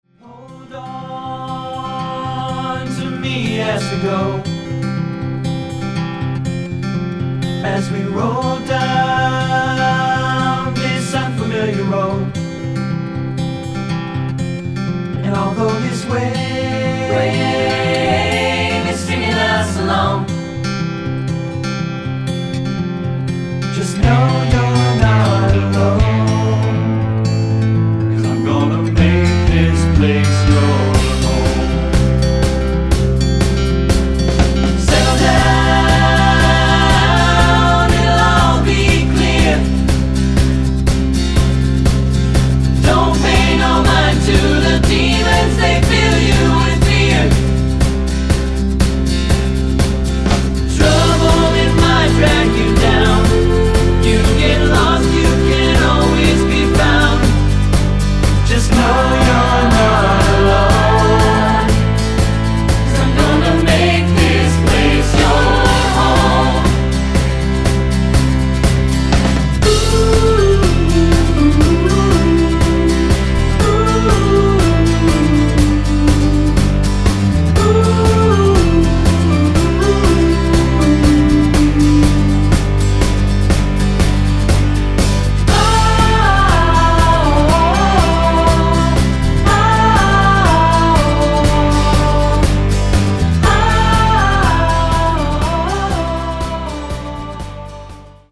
Voicing